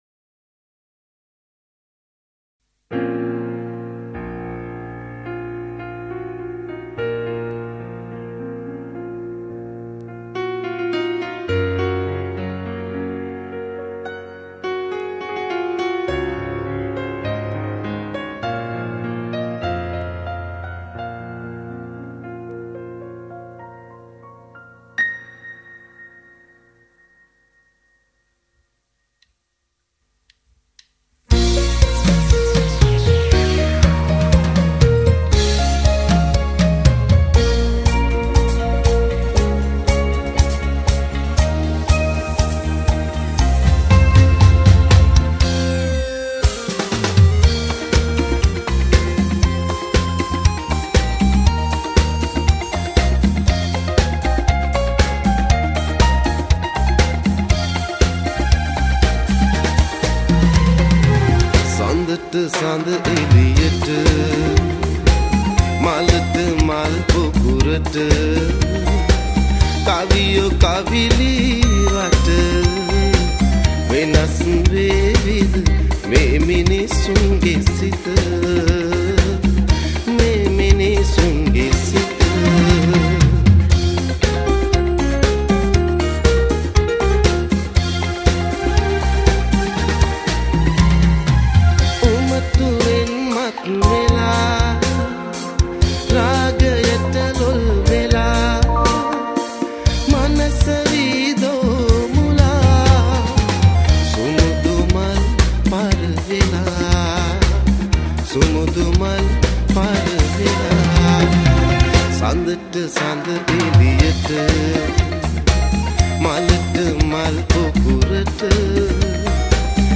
Keyboardist
Drummer
Bassist
Lead guitarist
Rhythm guitarist
Percussionist